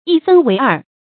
一分為二 注音： ㄧ ㄈㄣ ㄨㄟˊ ㄦˋ 讀音讀法： 意思解釋： 哲學用語，指事物作為矛盾的統一體，都包含著相互矛盾對立的兩個方面。